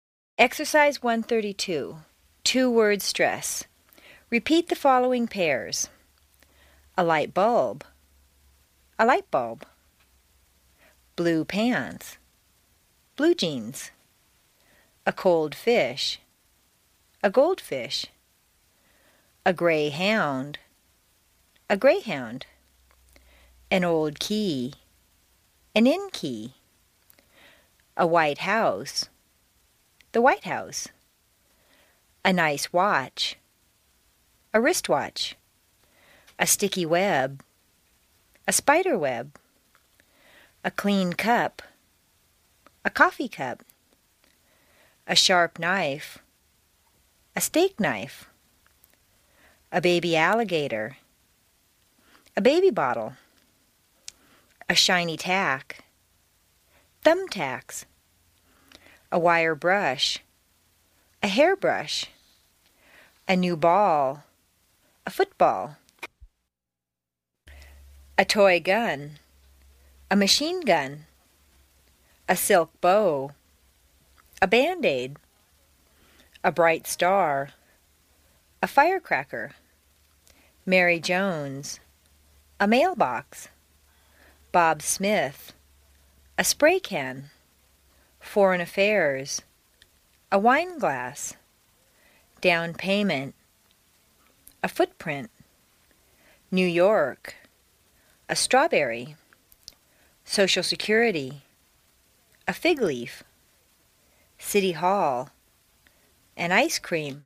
美式英语正音训练第26期:Exercise 1-32 Two-Word Stress 听力文件下载—在线英语听力室
在线英语听力室美式英语正音训练第26期:Exercise 1-32 Two-Word Stress的听力文件下载,详细解析美式语音语调，讲解美式发音的阶梯性语调训练方法，全方位了解美式发音的技巧与方法，练就一口纯正的美式发音！